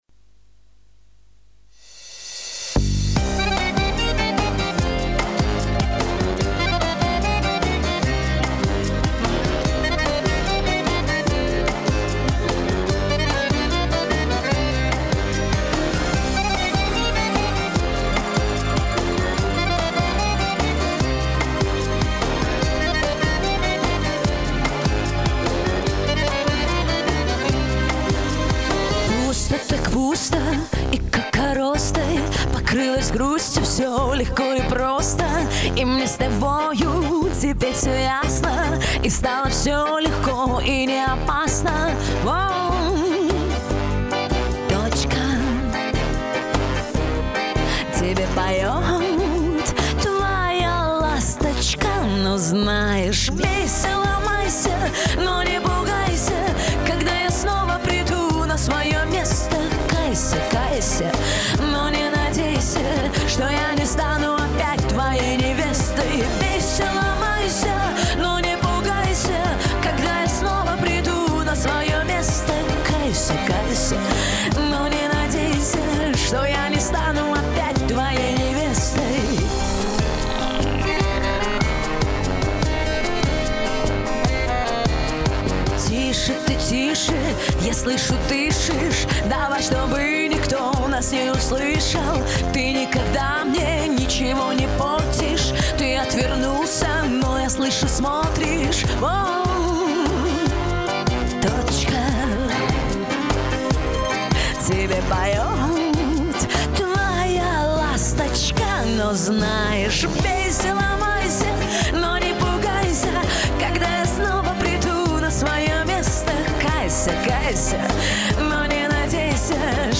звук оч мягкий)))